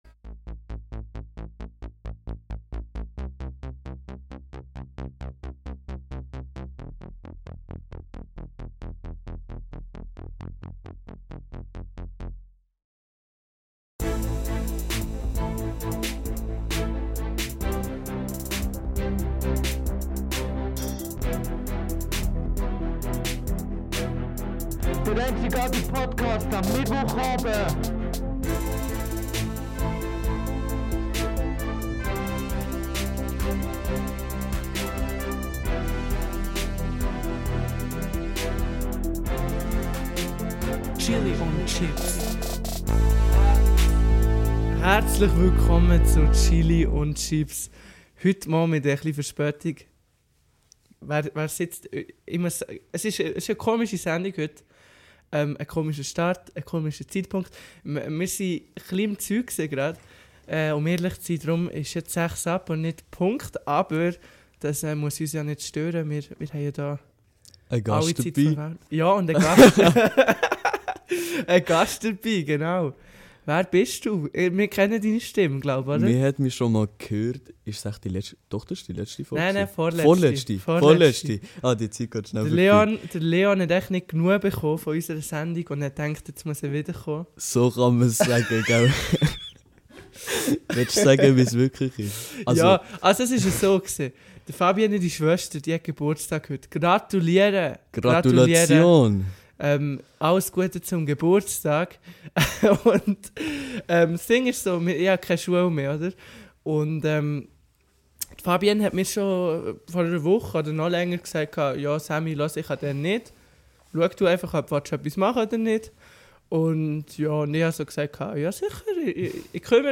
Der Live-Podacst